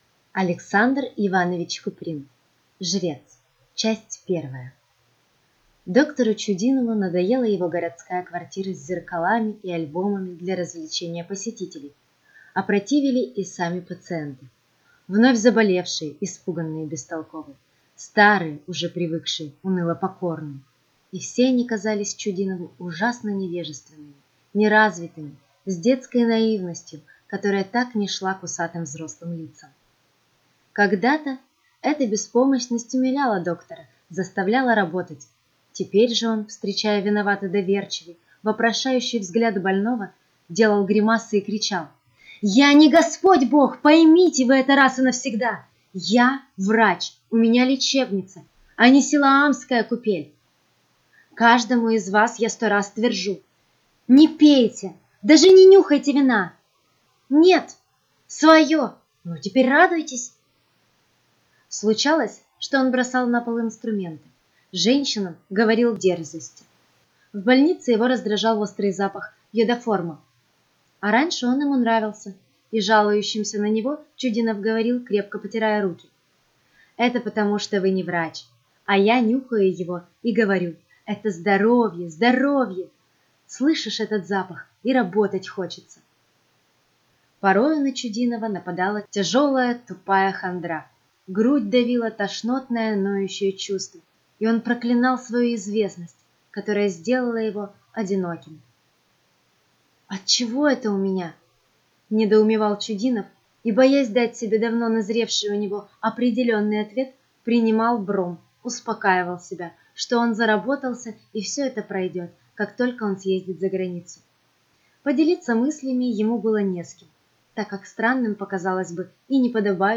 Aудиокнига Жрец